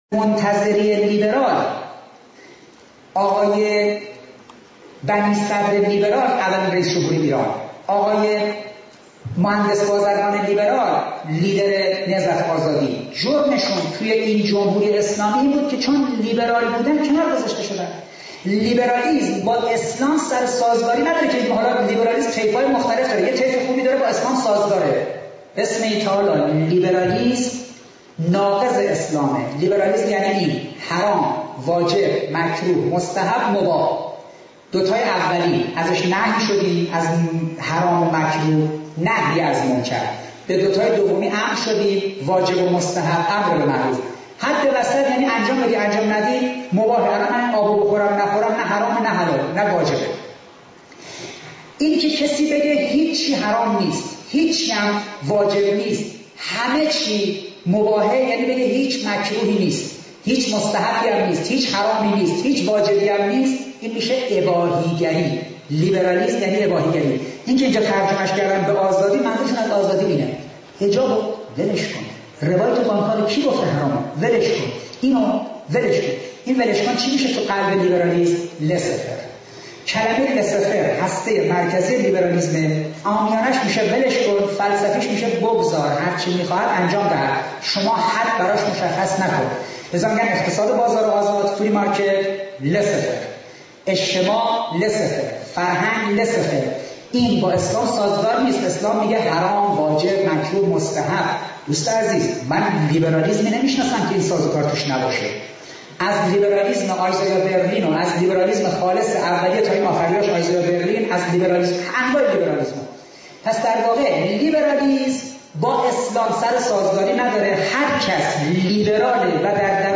کلیپ صوتی پاسخ قاطع و انقلابی و پرشور
پرشور